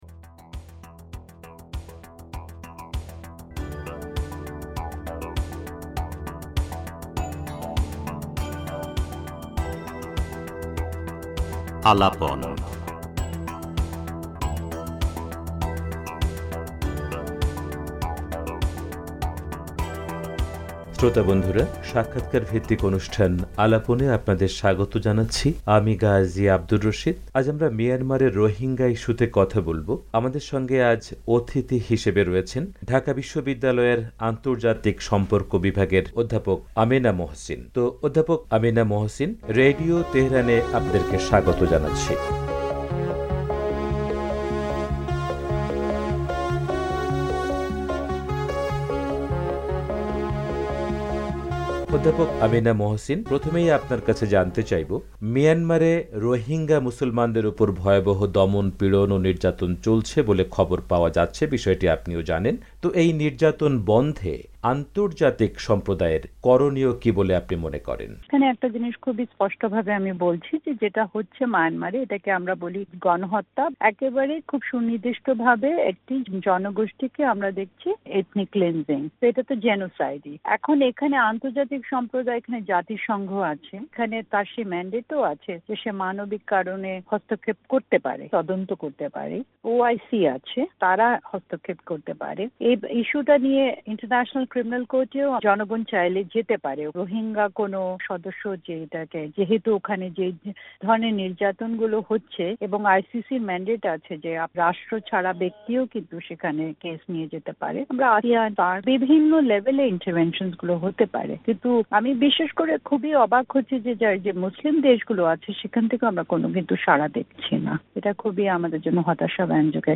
পুরো সাক্ষাৎকারটি উপস্থাপন করা হলো।